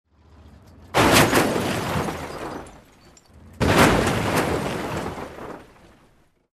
Звуки столкновения
7. Два звука удара автомобилей